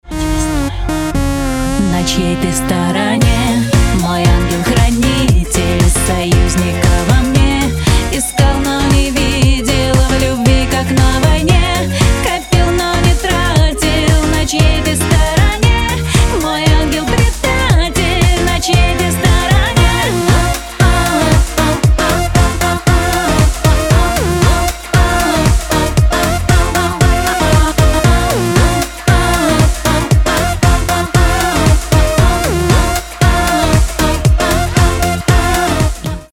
• Качество: 320, Stereo
попса
Винтаж